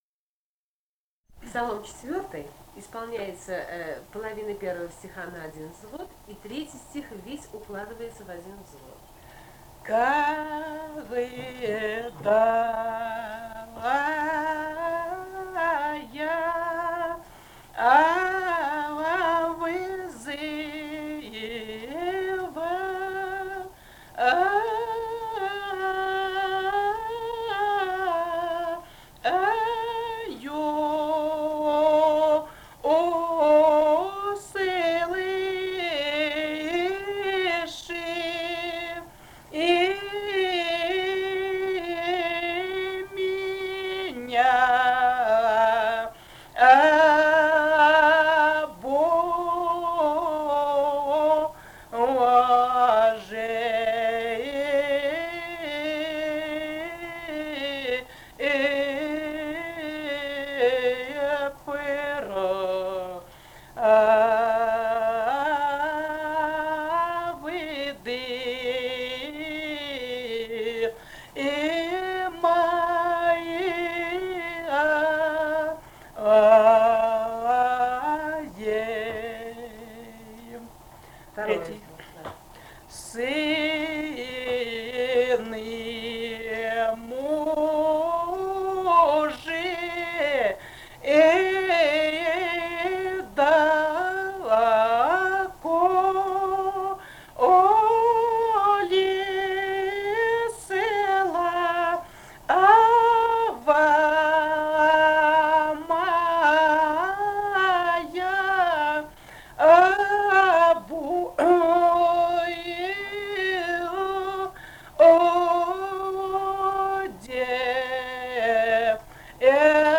Этномузыкологические исследования и полевые материалы
Грузия, г. Тбилиси, 1971 г. И1311-14